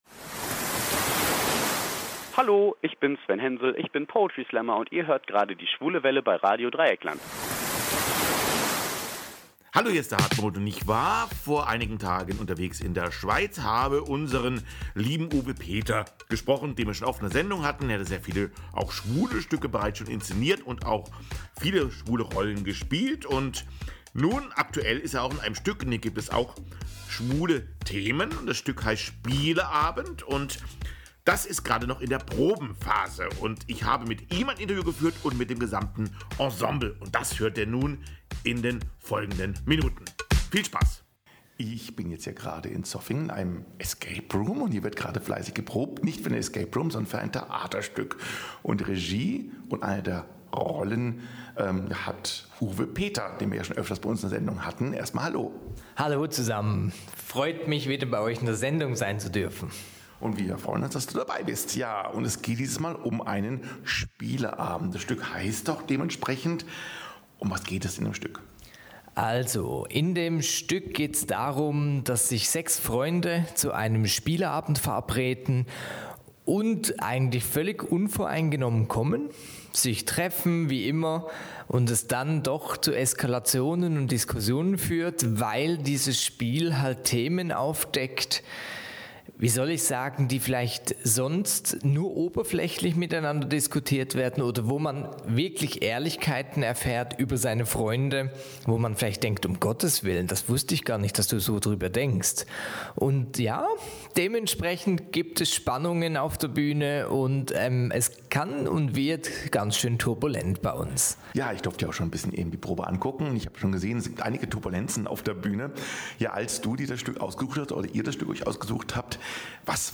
Dabei sprach er mit allen Schauspieler/innen über das Stück, die Inszenierung und natürlich … Spieleabende. Ebenso sprachen wir über den schwulen Handlungsstrang des Stückes.